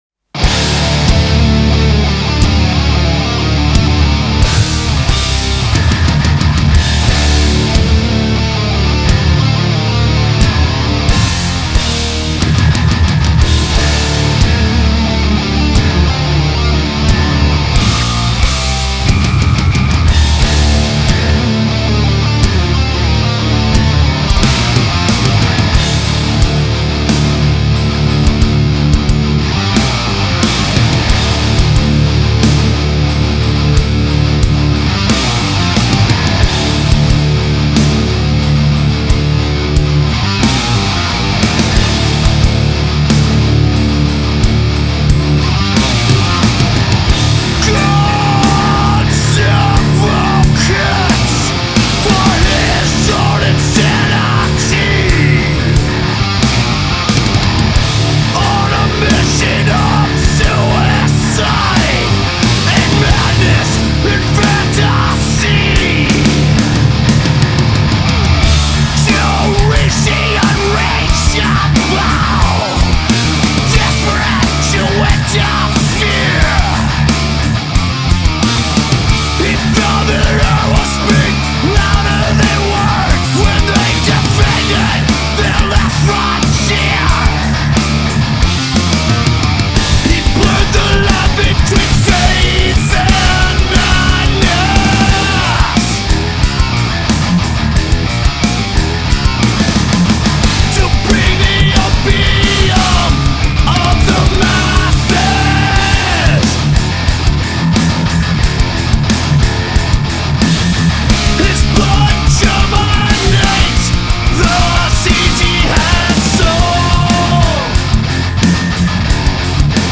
Трек размещён в разделе Зарубежная музыка / Метал.